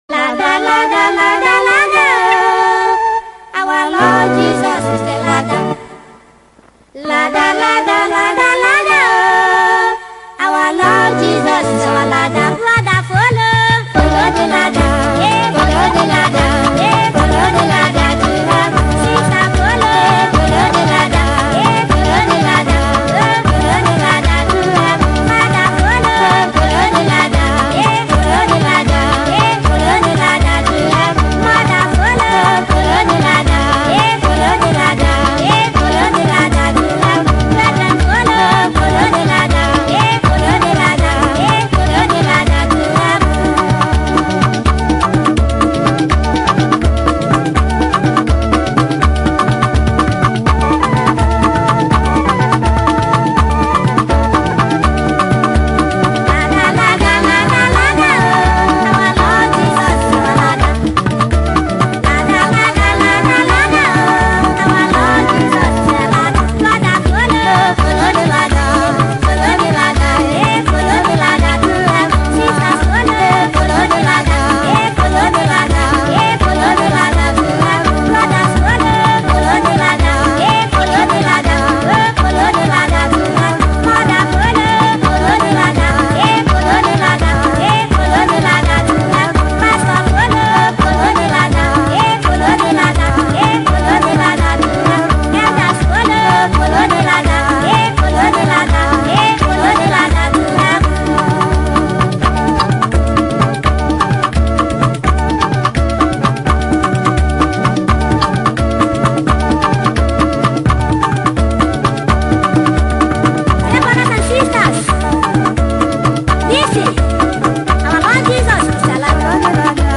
indigenous gospel singer